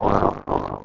1 channel
grunt2.mp3